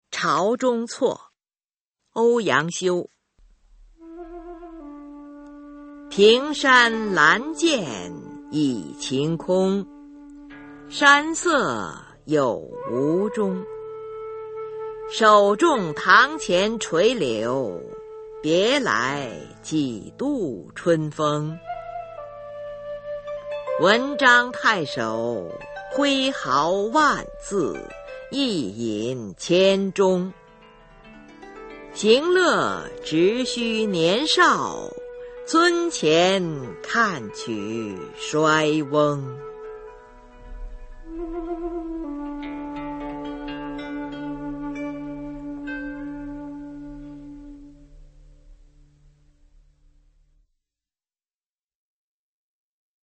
[宋代诗词朗诵]欧阳修-朝中措 古诗词诵读